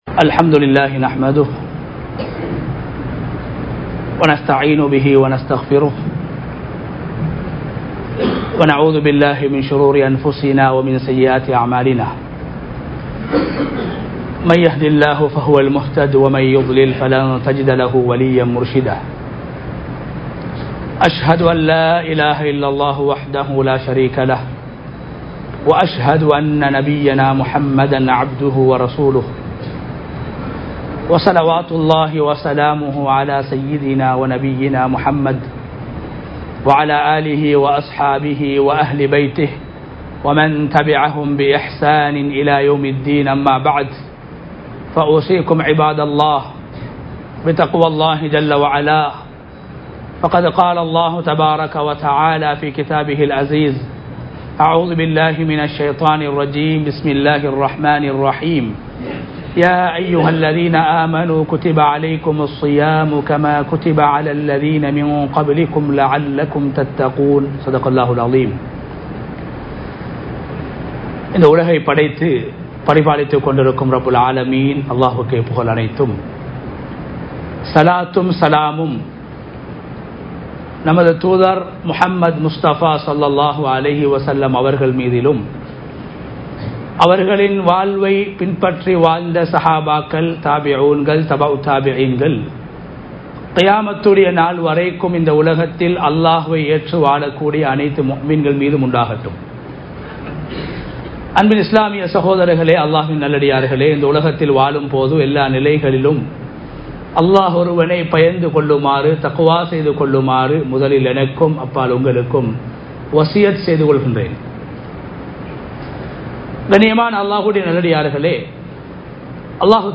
நாம் வாழ்வின் இறுதி ரமழானாக இது இருக்கலாம் | Audio Bayans | All Ceylon Muslim Youth Community | Addalaichenai